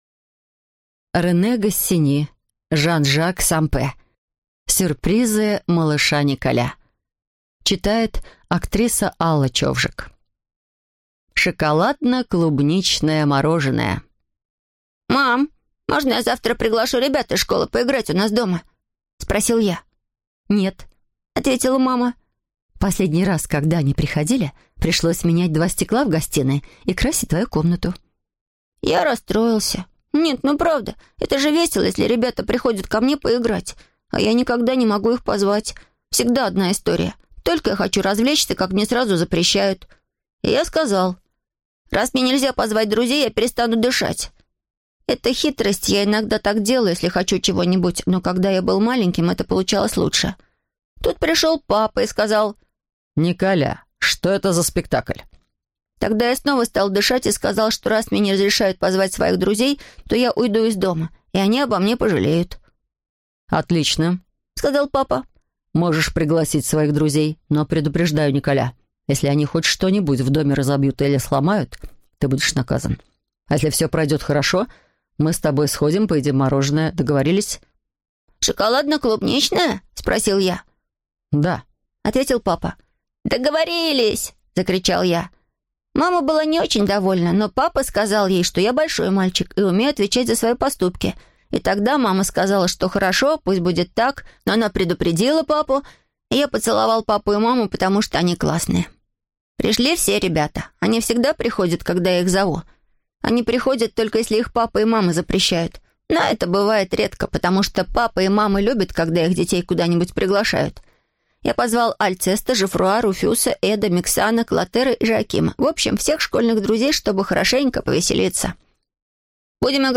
Аудиокнига Сюрпризы малыша Николя | Библиотека аудиокниг